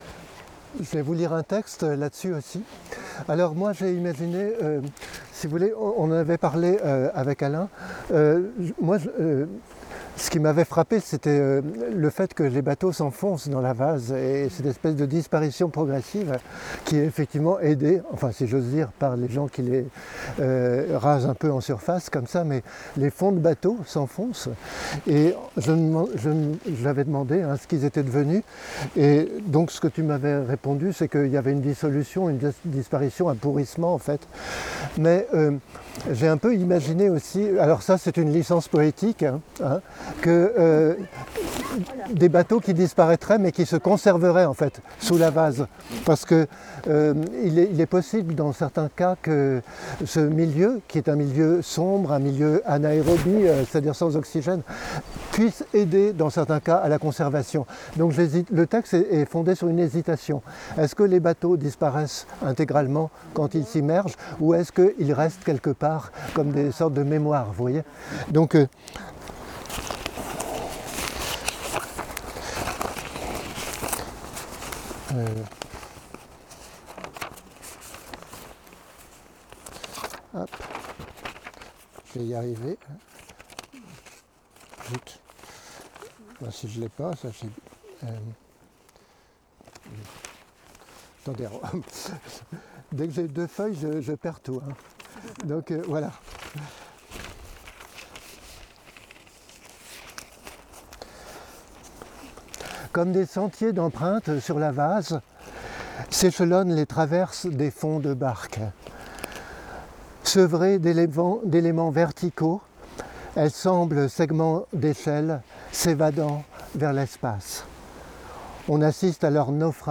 lecture de texte sur le Cimetière de bateaux de Pluneret, 27 septembre 2020